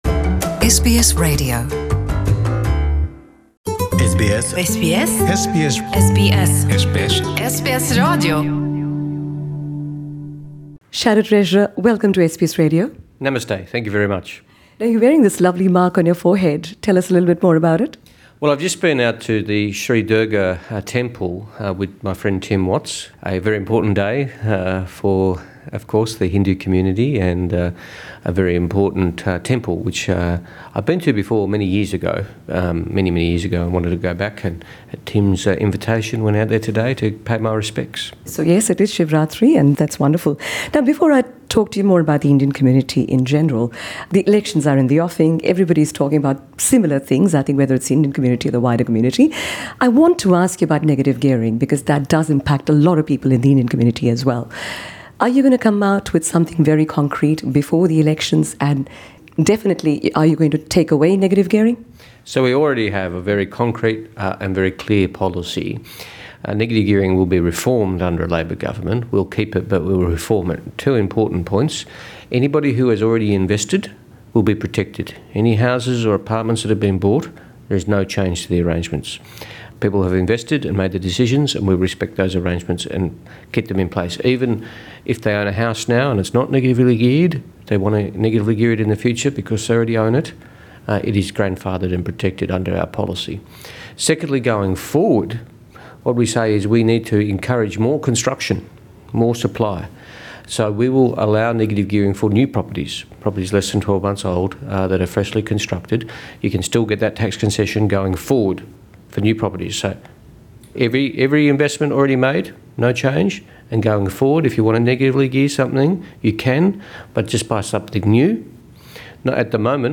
Shadow Treasurer, Chris Bowen speaking to SBS Punjabi on Monday, 4 March 2019 Source: SBS Punjabi